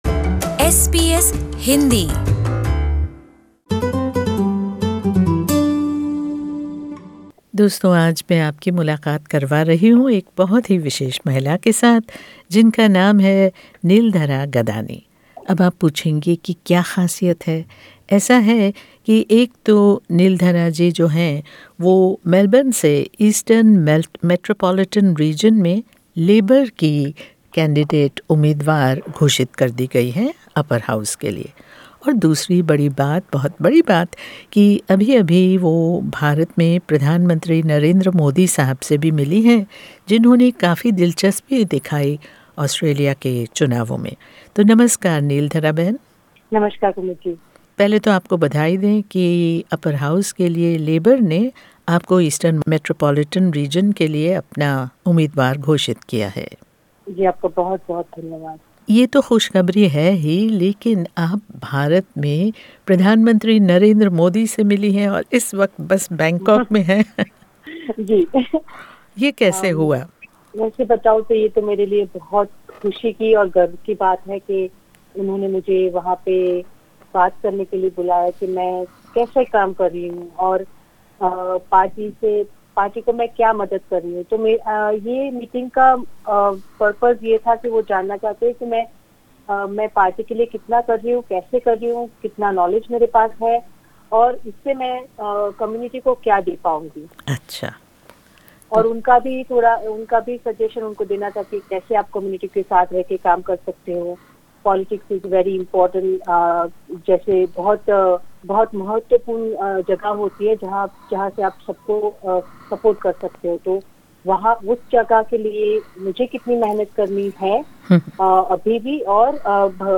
while she was at the Bangkok airport on her way back to Melbourne.